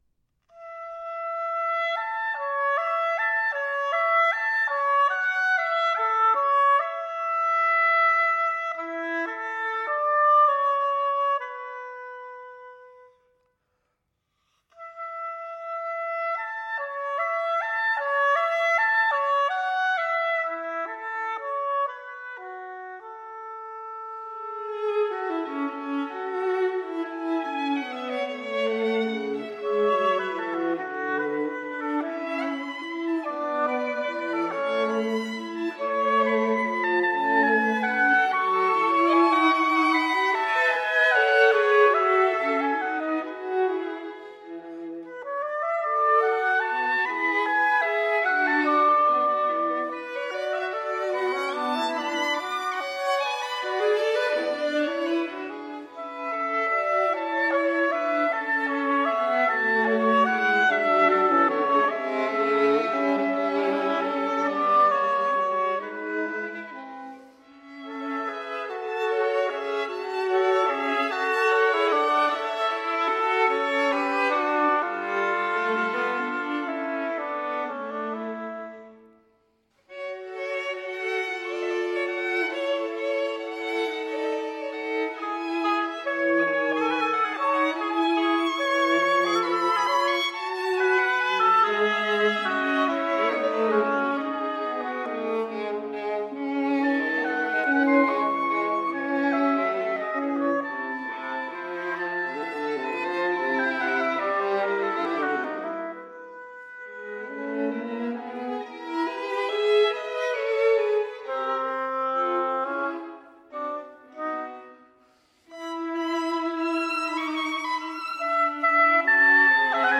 Viola